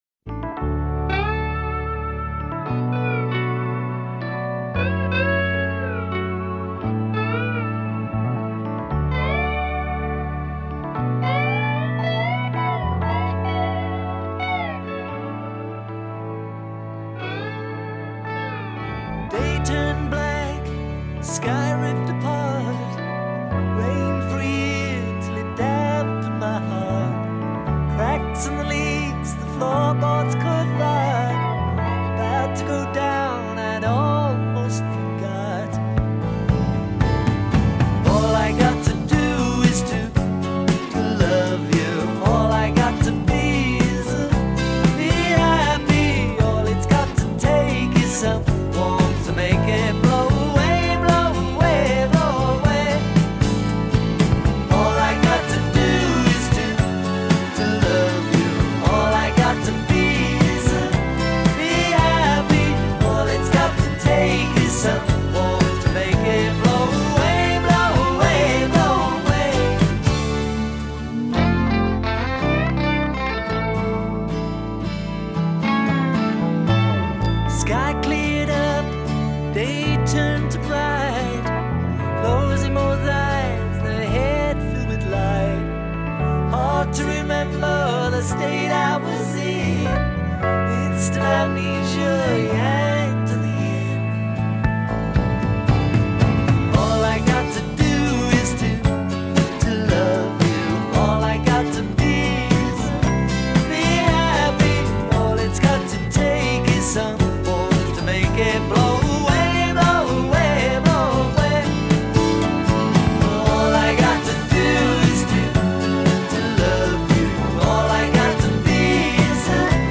es una canción muy pop
Para tiempos brumosos, una pequeña canción optimista